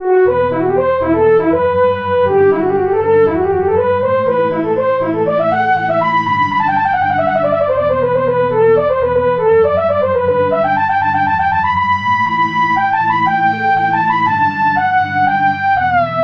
Fanfare melody.